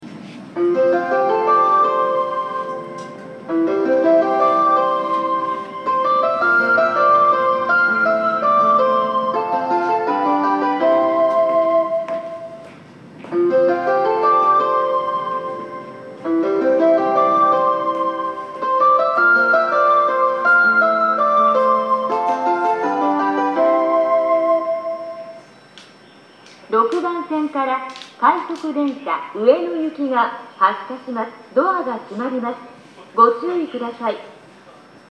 成田駅　Narita Station ◆スピーカー：CLD標準型
全電車が始発のため、余韻までは鳴りやすいですが、フル以上は粘りが必要です。
6番線発車メロディー